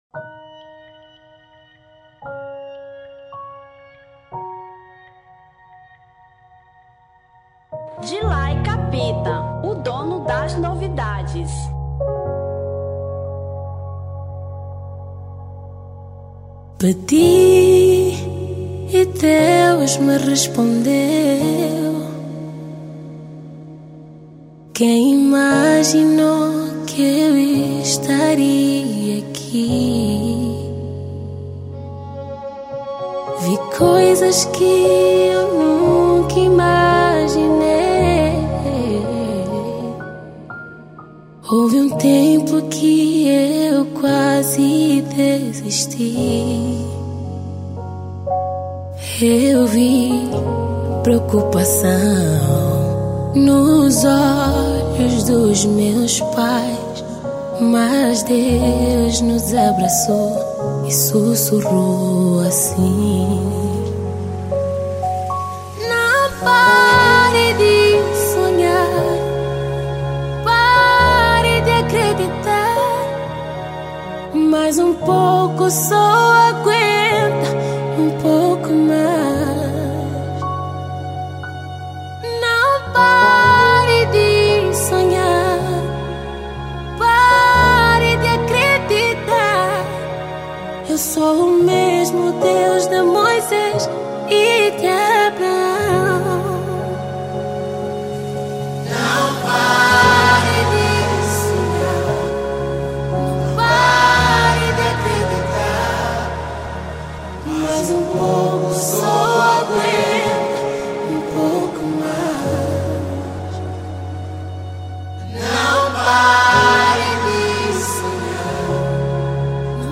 Soul 2025